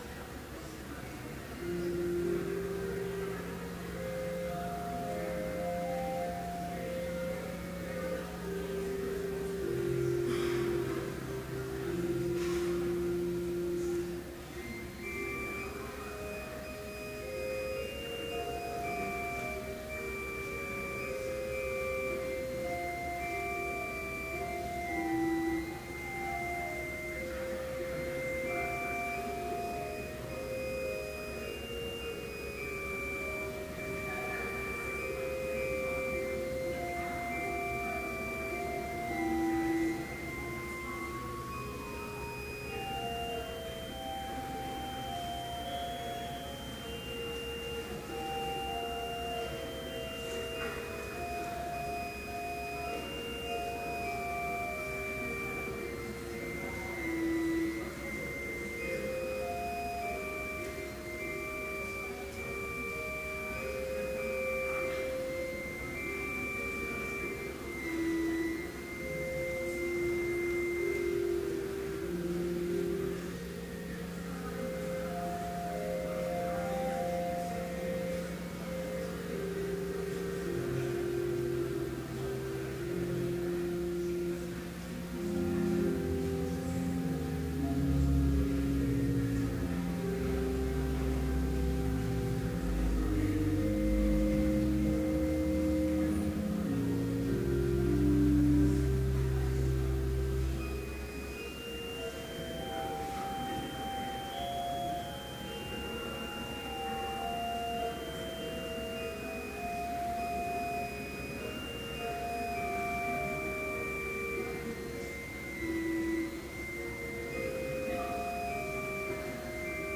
Complete service audio for Chapel - February 13, 2013